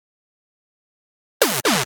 Fill 128 BPM (26).wav